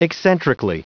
Prononciation du mot eccentrically en anglais (fichier audio)
Prononciation du mot : eccentrically